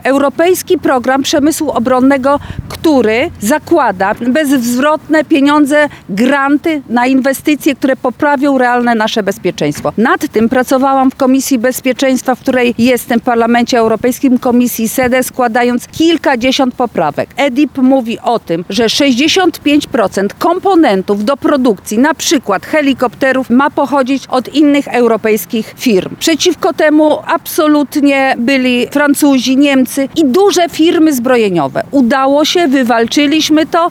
– To poprawi nasze bezpieczeństwo – mówi europosłanka Marta Wcisło.